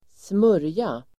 Uttal: [²sm'ör:ja]